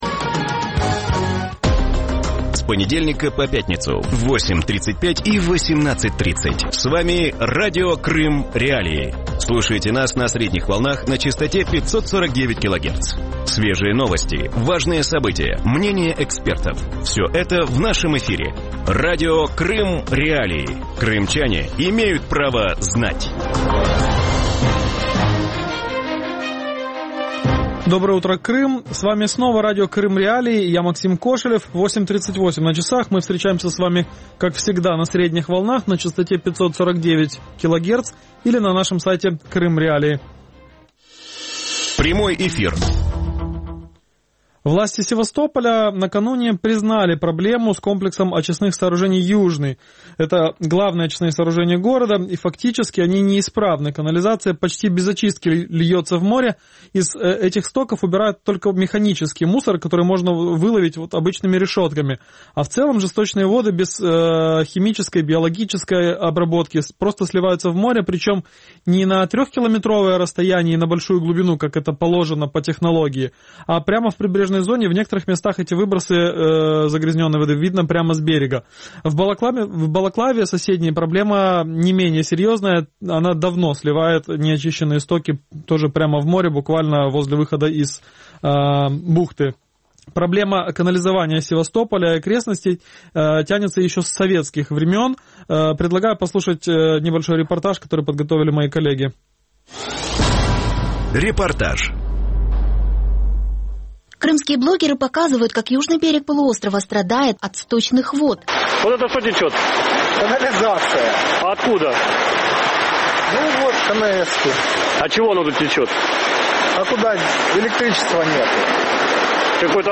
Утром в эфире Радио Крым.Реалии говорят о сбросе нечистот вблизи побережья полуострова.